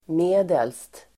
Uttal: [m'e:del:st]